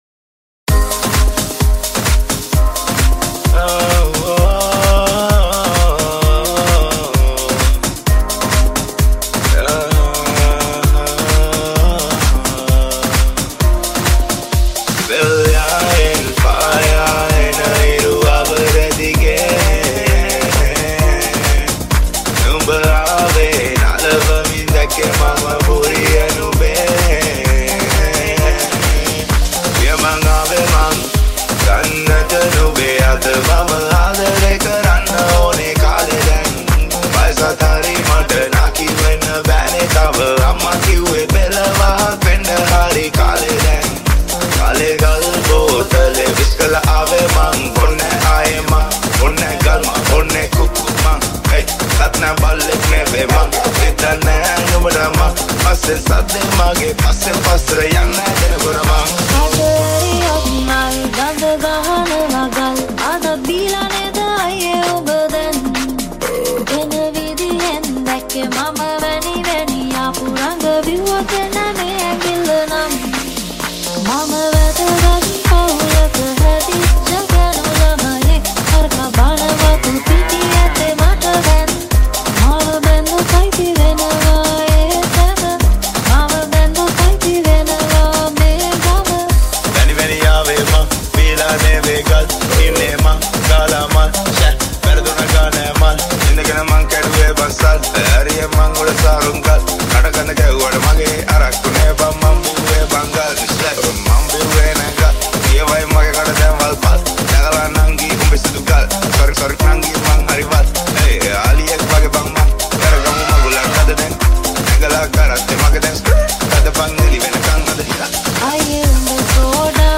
new remix song